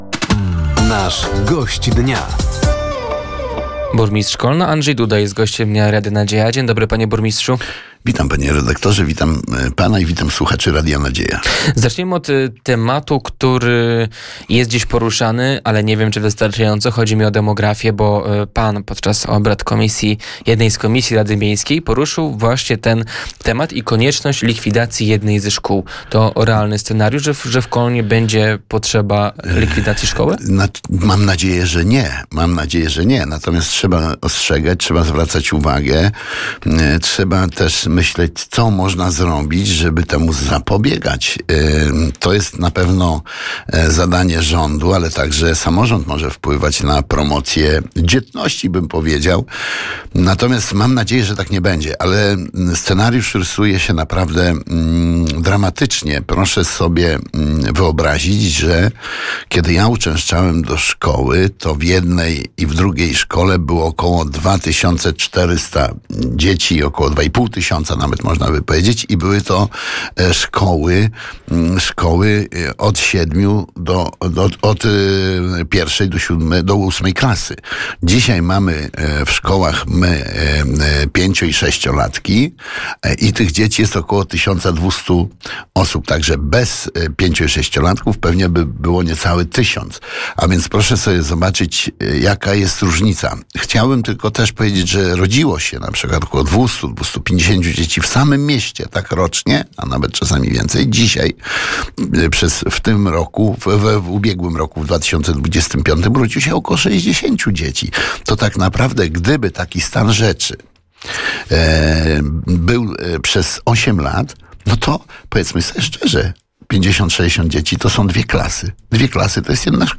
Gościem Dnia Radia Nadzieja był burmistrz Kolna Andrzej Duda. Tematem rozmowy była między innymi sytuacja demograficzna miasta, inwestycje związane z OZE oraz system kaucyjny,